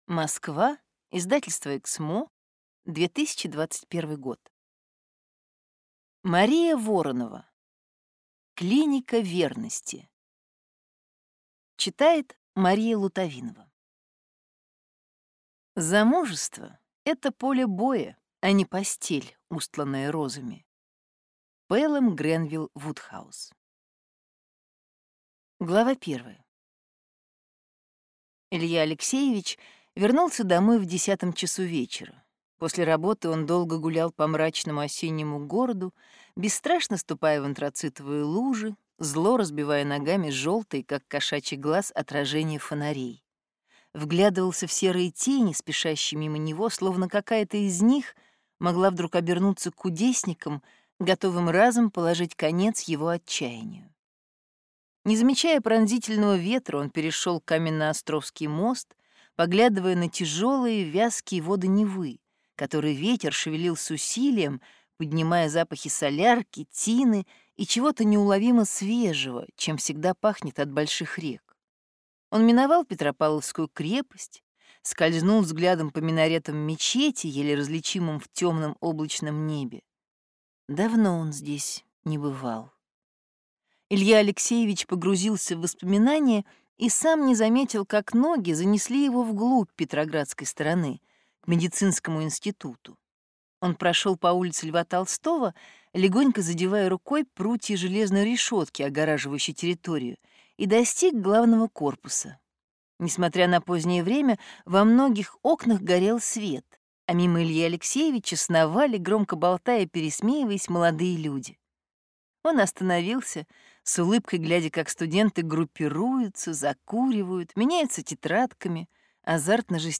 Аудиокнига Клиника верности | Библиотека аудиокниг